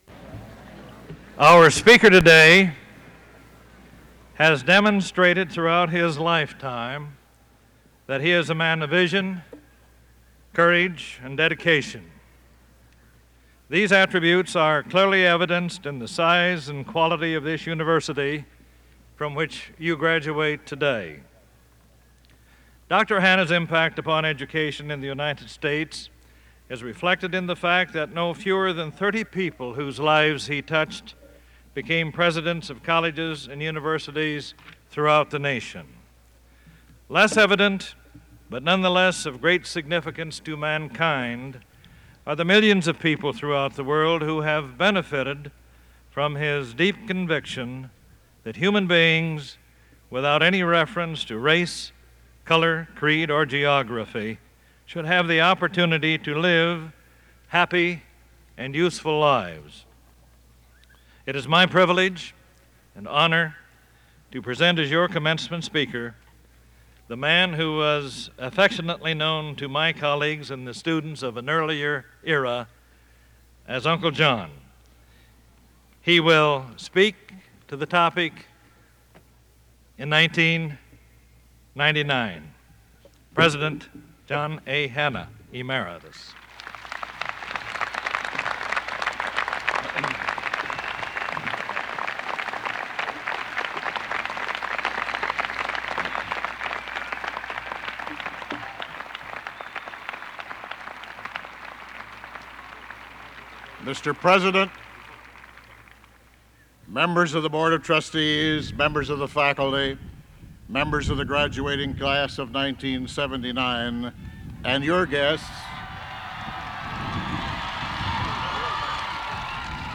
Commencement Address: John A. Hannah, 1979
Commencement Address: John A. Hannah, 1979 Back Creator: WKAR Subjects: Administration, Board of Trustees, Students, Presidents, East Lansing, Commencements Description: John A. Hannah gives the commencement address at the Spring 1979 graduation ceremony, and is given an honorary Doctorate of Humanities.
Original Format: Open reel audio tape